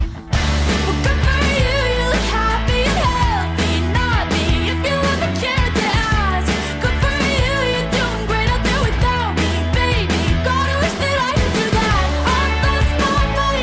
strong vocals